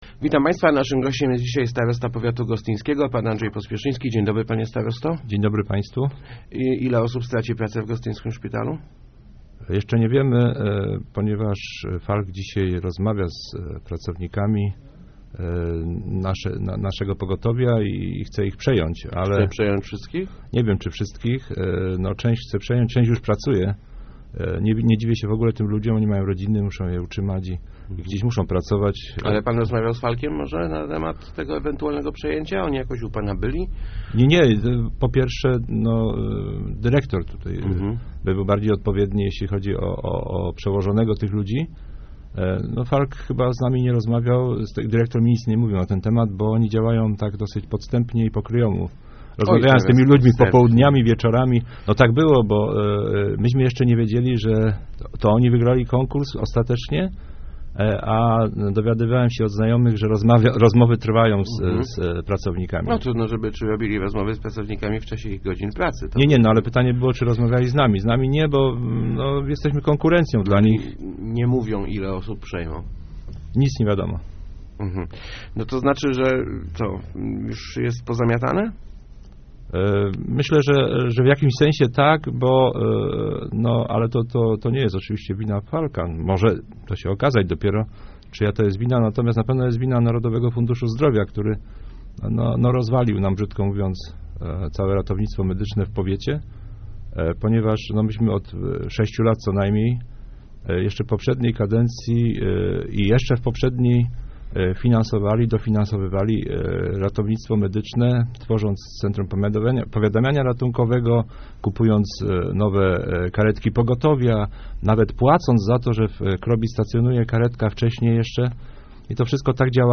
NFZ rozwalił nam ratownictwo medyczne - mówił w Rozmowach Elki starosta gostyński Andrzej Pospieszyński. Jego zdaniem ktoś majstrował przy dokumentacji przetargu, w wyniku czego wygrała oferta firmy Falck.